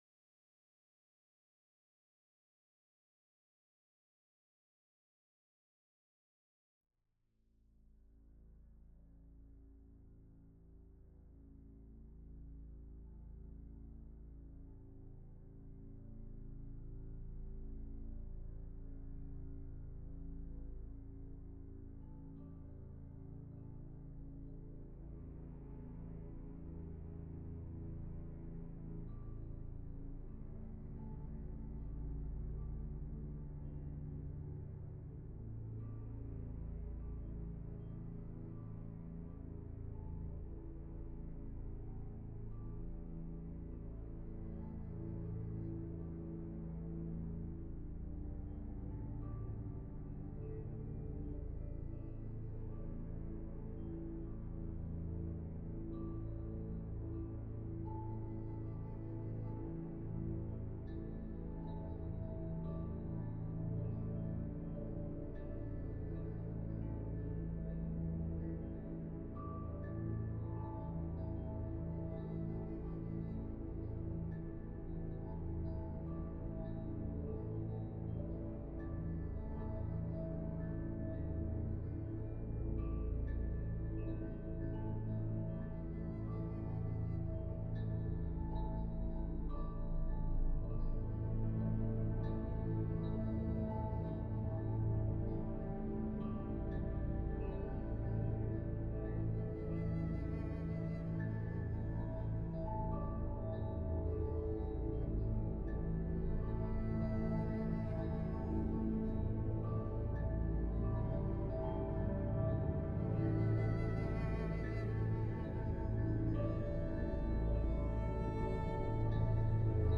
the score for the documentary